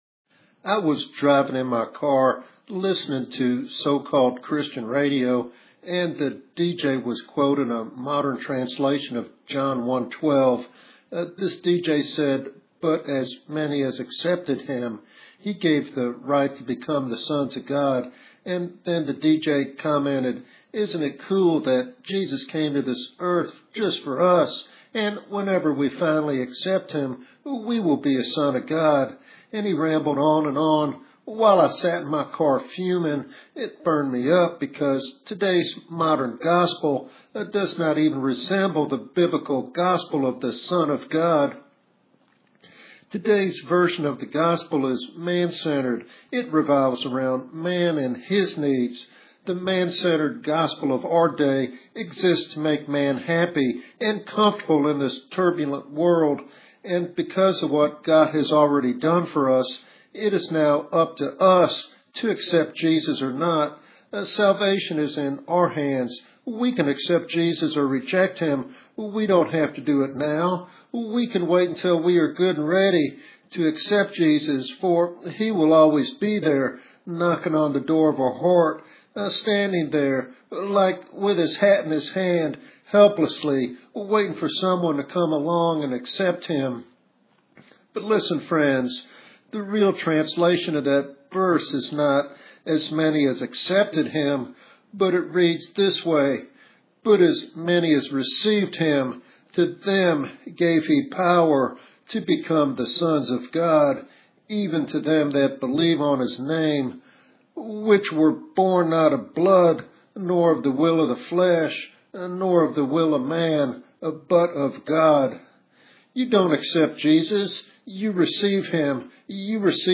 This sermon is a clarion call to embrace the gospel as God intended it—centered on His glory and power.